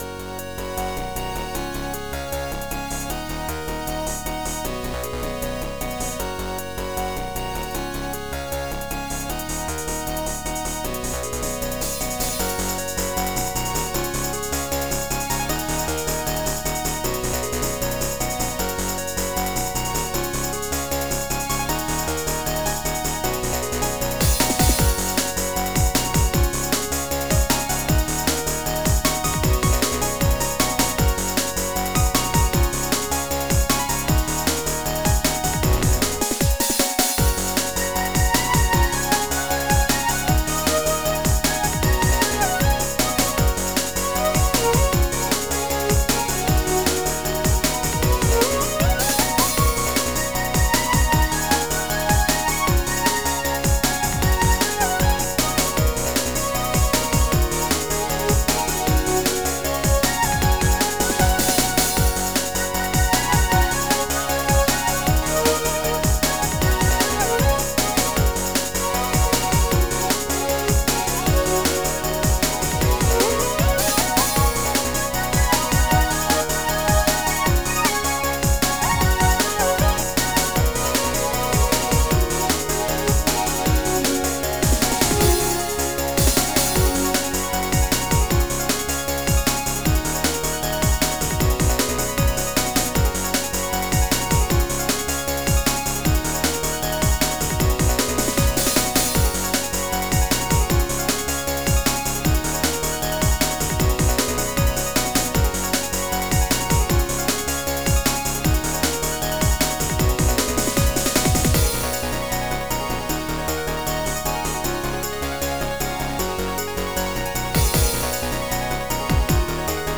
Style: Chippop
Plus it has an 8-bit feel, and I've always loved 8-bit!